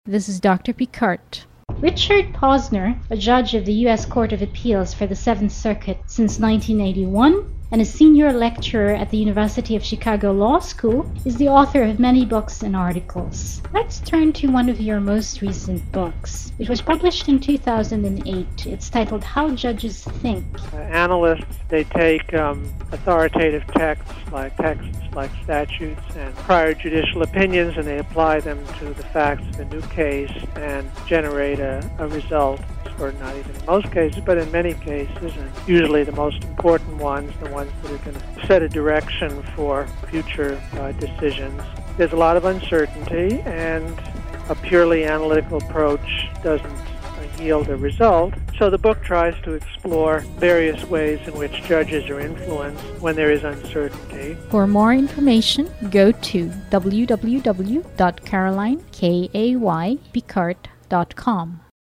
Richard Posner, Judge, 7th Circuit Court of Appeals October 24, 2008 Short Promo 1,